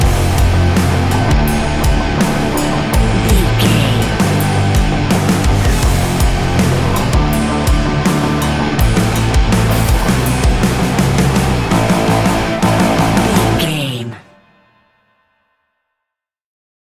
Ionian/Major
hard rock
instrumentals